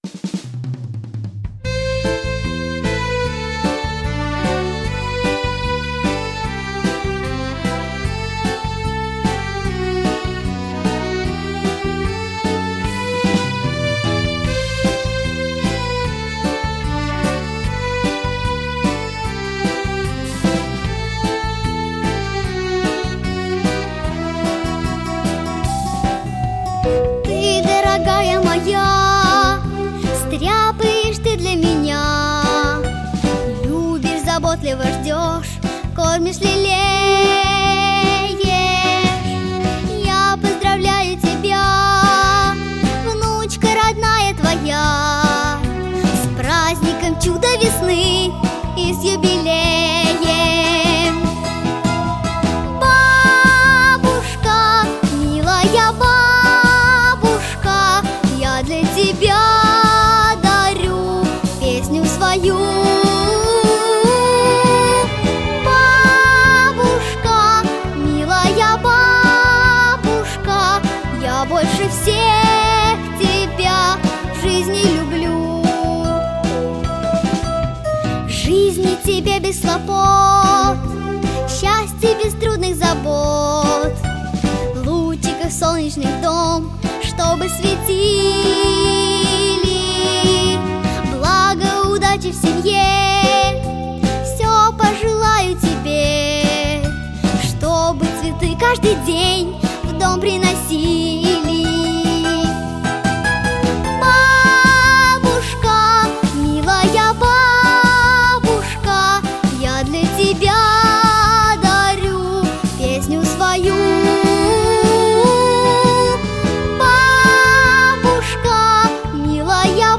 • Категория: Детские песни / Песни про бабушку